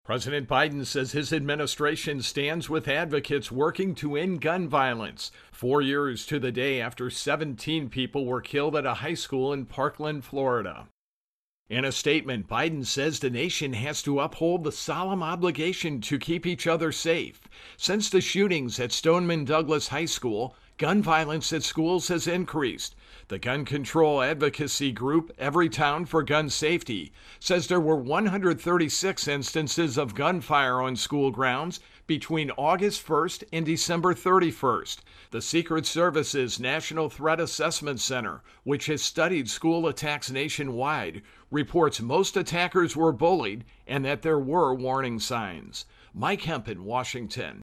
Intro and voicer on Biden Guns